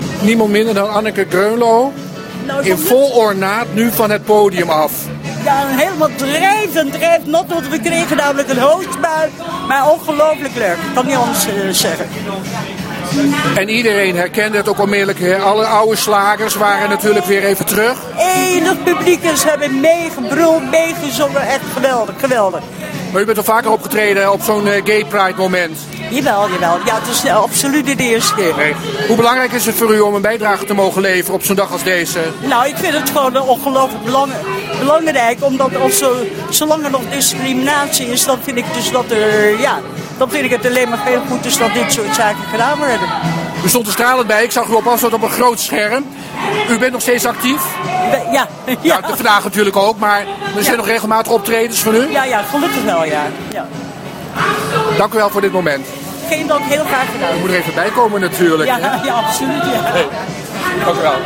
Maandagmorgen zijn al vroeg op het Rembrandtplein de laatste herinneringen aan een geslaagde Gay Pride 2012 opgeruimd. Zondagmiddag ging een slotfeest van start dat nog laat in de avond doorklonk. Opvallend was het indrukwekkende optreden van Anneke Grönloh (70).